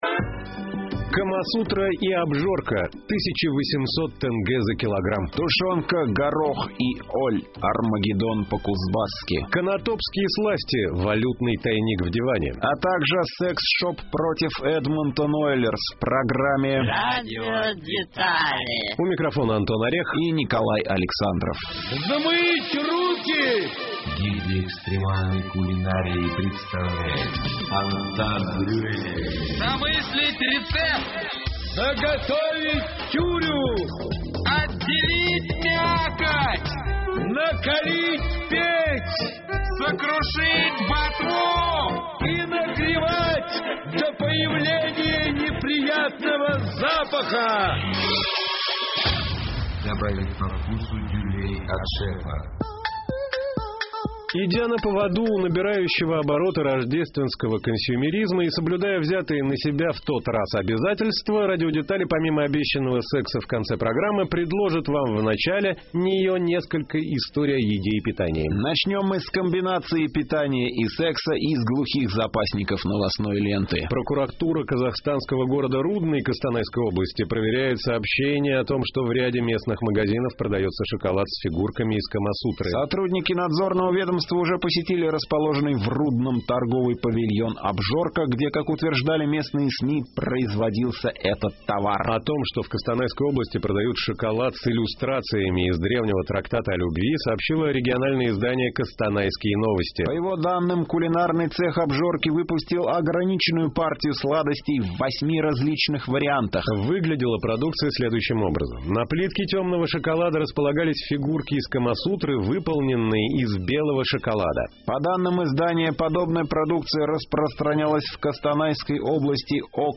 У микрофона Антон Орех